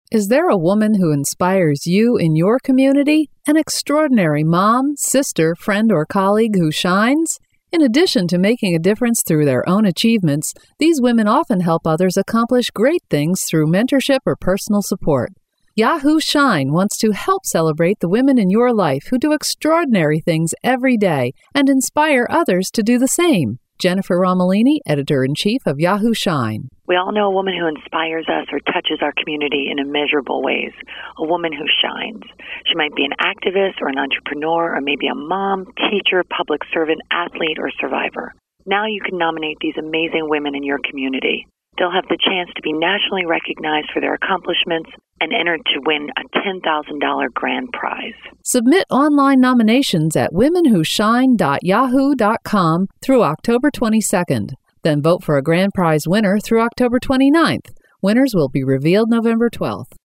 September 11, 2012Posted in: Audio News Release